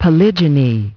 Transcription and pronunciation of the word "polygyny" in British and American variants.